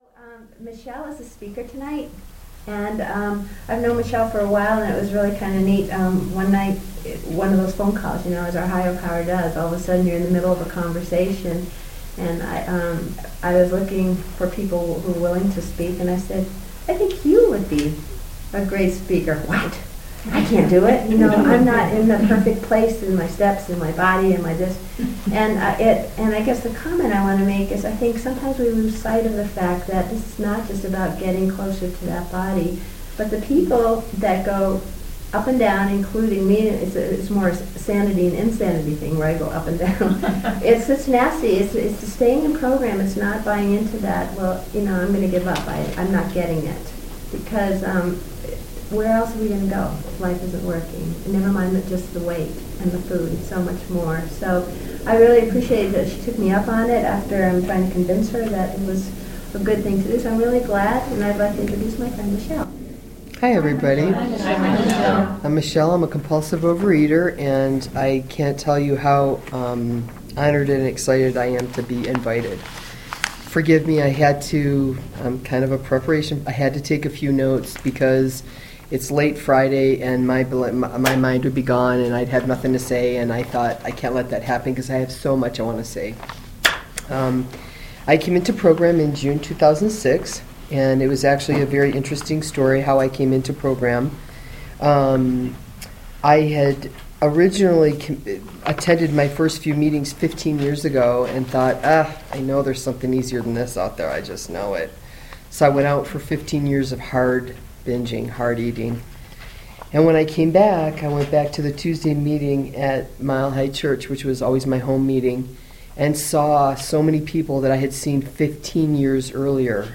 Speakers Meeting